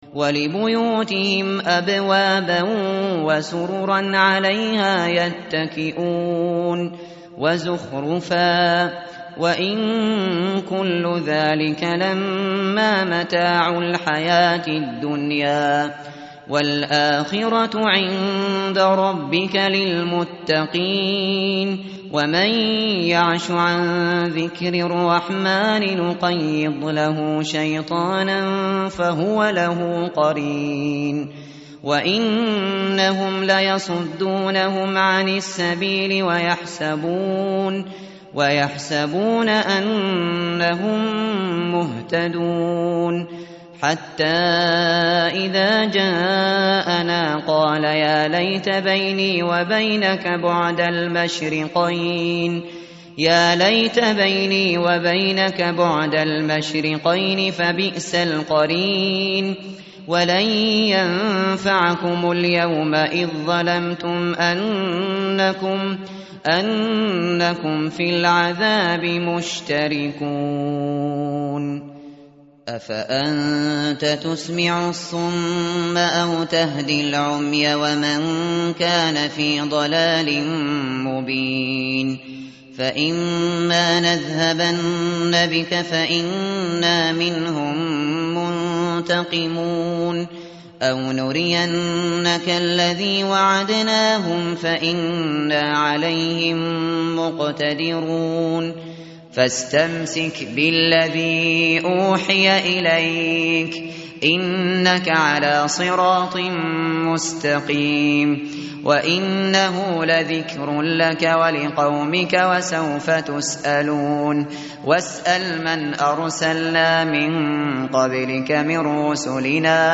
متن قرآن همراه باتلاوت قرآن و ترجمه
tartil_shateri_page_492.mp3